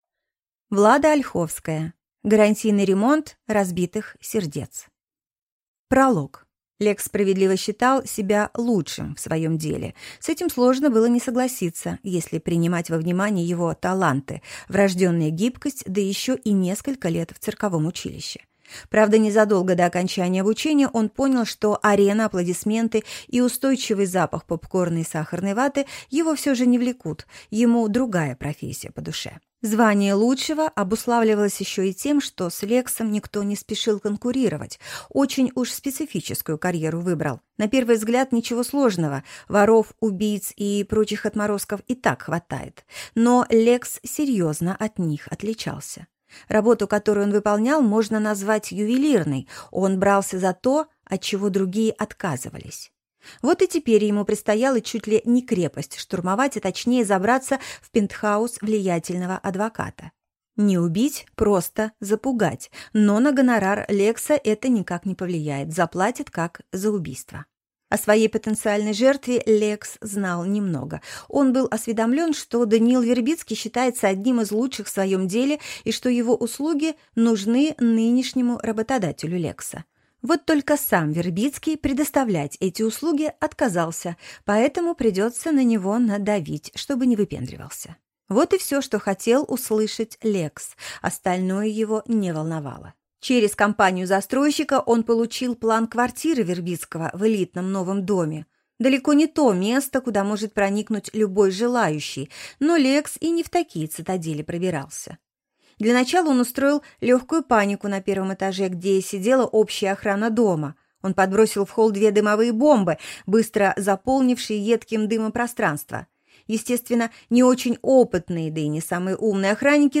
Аудиокнига Гарантийный ремонт разбитых сердец | Библиотека аудиокниг